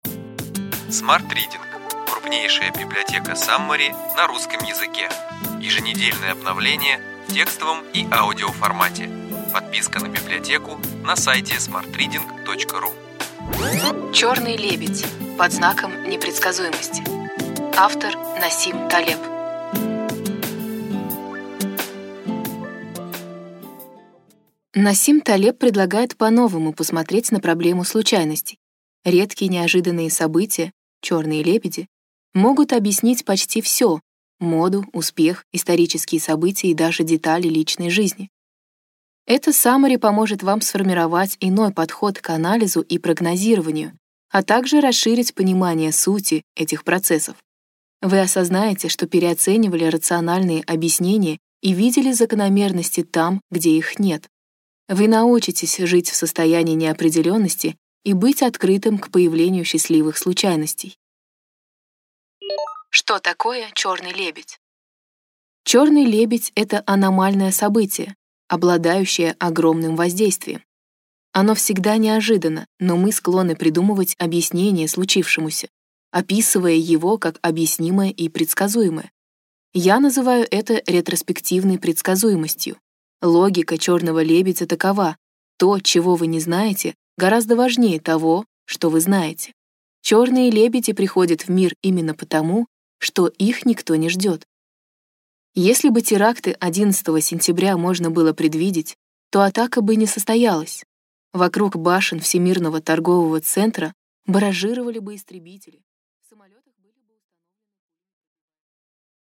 Аудиокнига Ключевые идеи книги: Черный лебедь. Под знаком непредсказуемости.